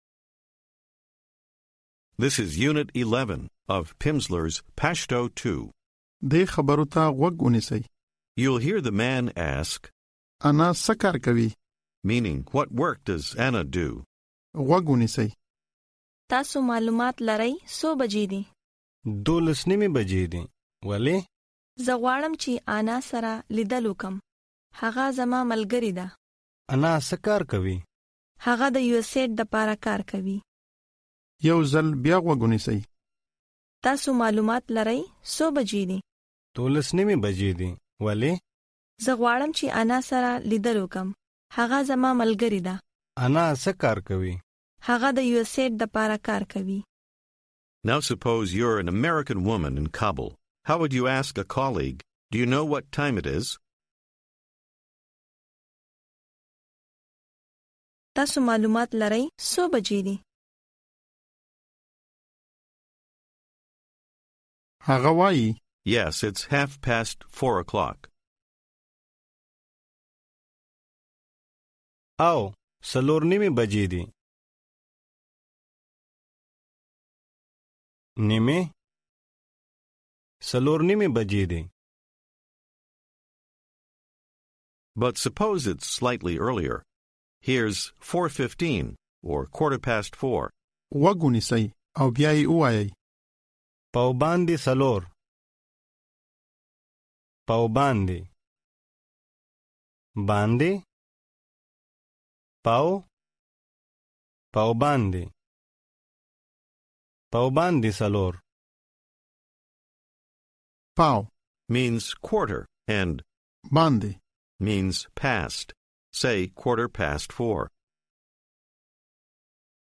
Audiobook
This course includes Lessons 11-15 from the Pashto Level 2 Program featuring 2.5 hours of language instruction. Each lesson provides 30 minutes of spoken language practice, with an introductory conversation, and new vocabulary and structures.